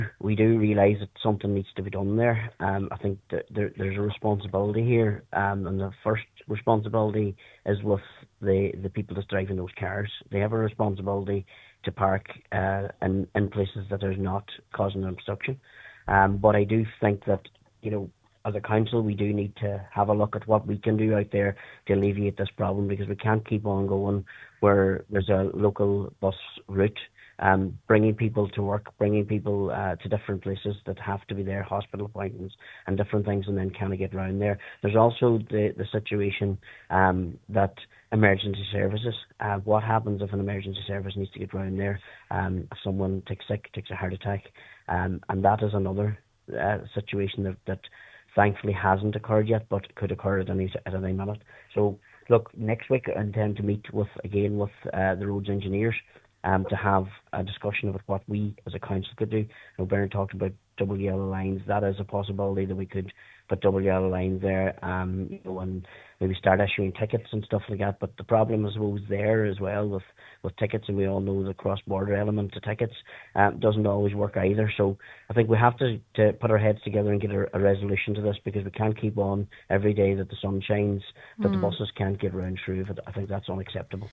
on this Morning’s Nine til Noon Show, Cllr Martin McDermott says the Council must put their heads together to think of a solution.